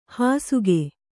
♪ hāsuge